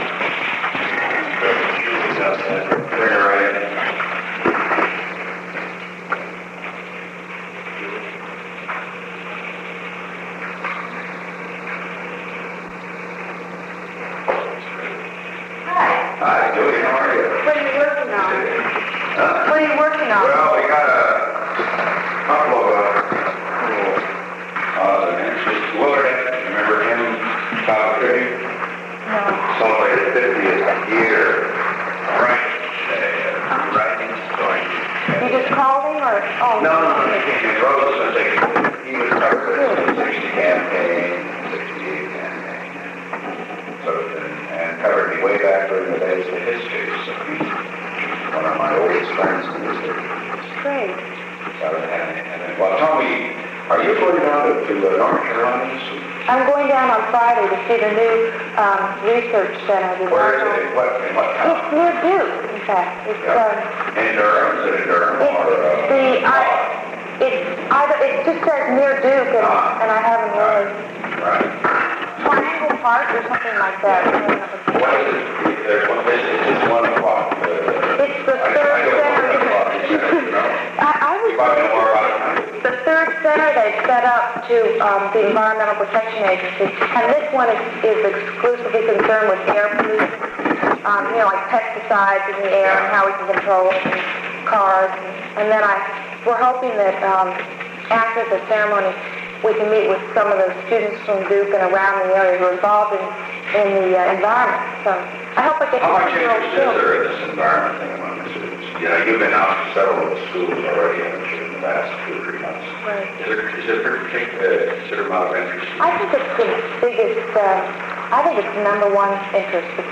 The Oval Office taping system captured this recording, which is known as Conversation 630-008 of the White House Tapes.